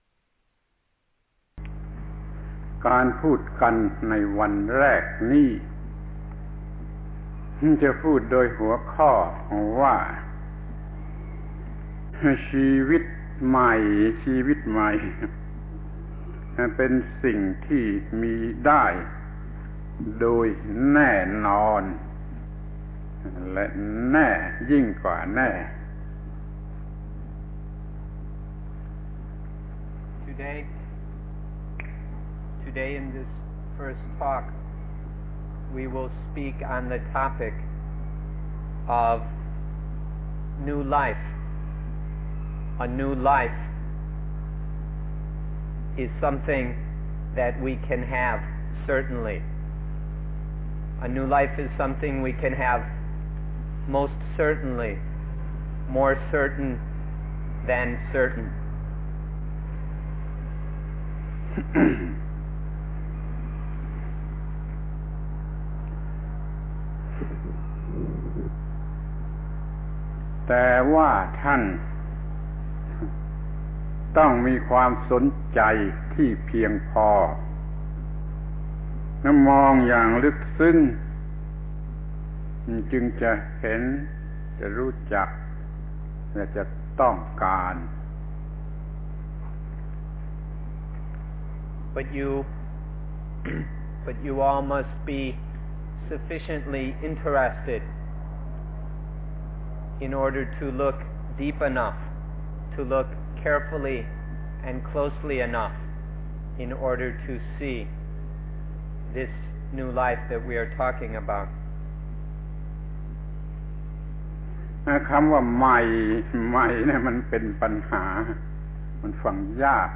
พระธรรมโกศาจารย์ (พุทธทาสภิกขุ) - อบรมผู้ปฏิบัติจิตตภาวนาชาวต่างประเทศ 2530 ครั้ง ๑ ชีวิตใหม่